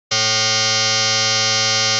IKONKA GŁOśNIKA Przykład częstotliwości podstawowej 440 Hz i modulującej 330 Hz